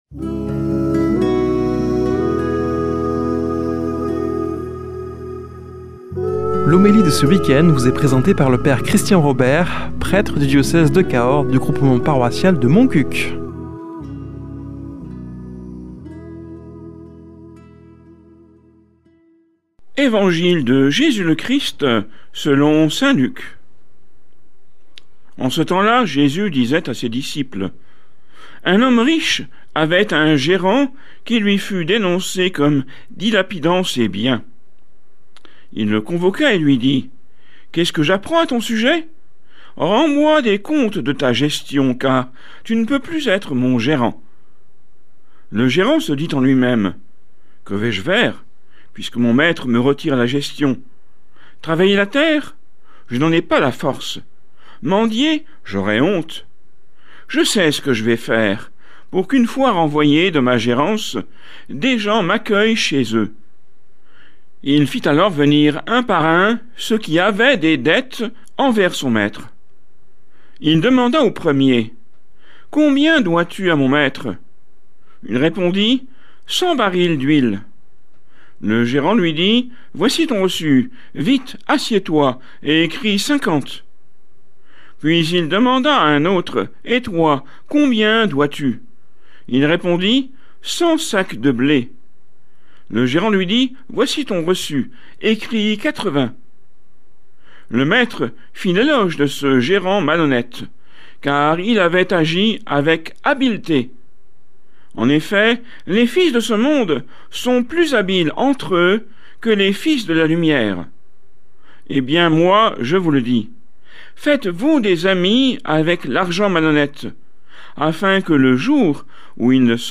Homélie du 20 sept.